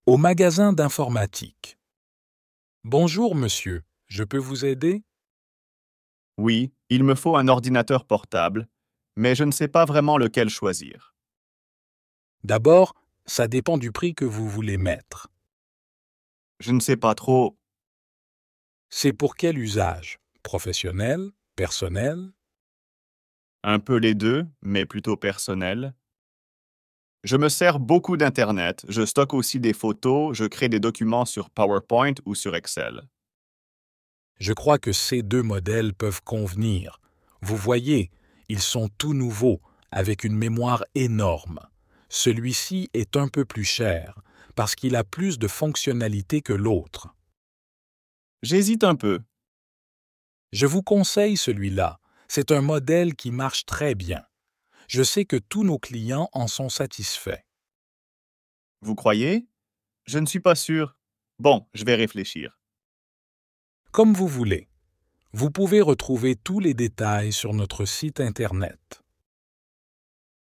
Dialogue en français – Au magasin informatique (A2)